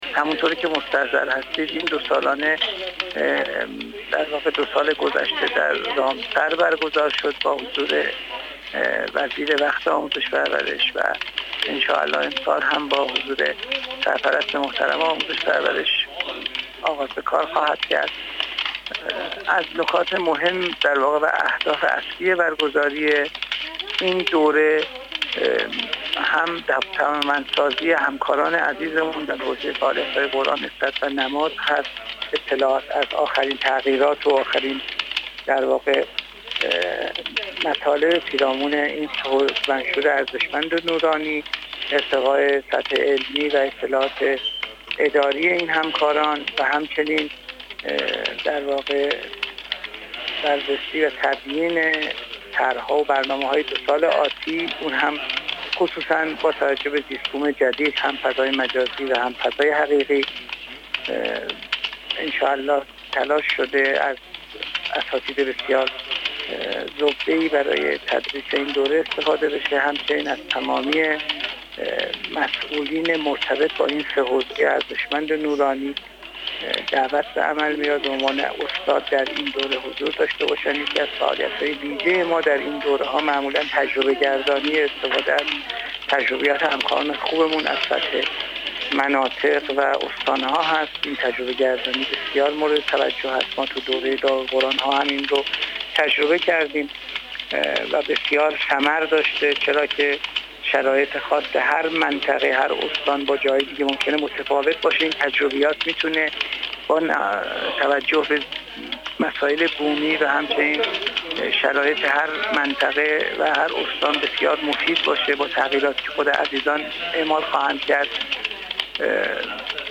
محمدرضا مسیب‌زاده، مدیرکل قرآن، عترت و نماز وزارت آموزش و پرورش در گفت‌وگو با ایکنا، ضمن بیان این مطلب گفت: چهارمین دوره آموزشی و توانمندسازی رؤسا، کارشناسان مسئول و کارشناسان قرآن، عترت و نماز سراسر کشور، جمع بیش از هزار نفر از تمامی کارشناسان مناطق و استان‌ها از 26 تا 28 مهرماه برگزار می‌شود.